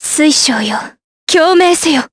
Talisha-Vox_Skill5_Jp.wav